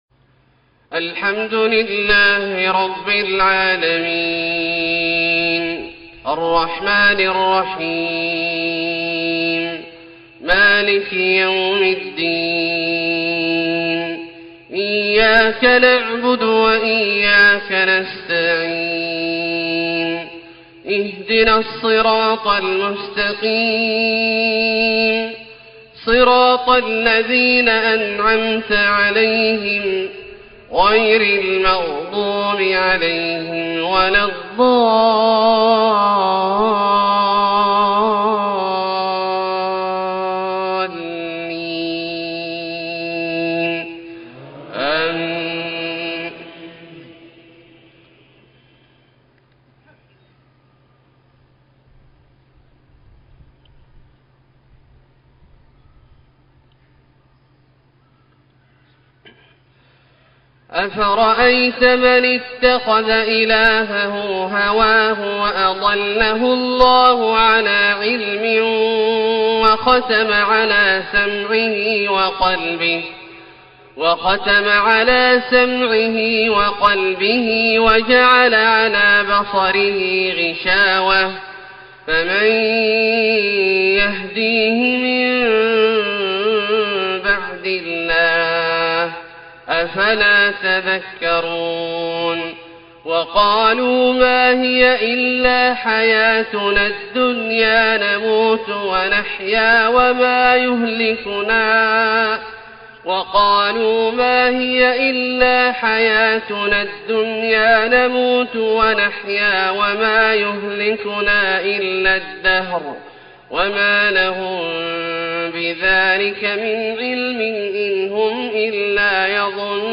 صلاة الفجر15 محرم 1433من سورتي الجاثية{23-37} ومحمد{33-38} > ١٤٣٣ هـ > الفروض - تلاوات عبدالله الجهني